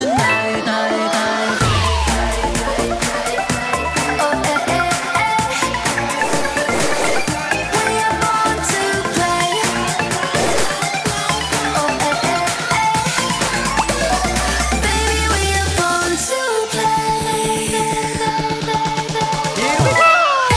A sample of the first chorus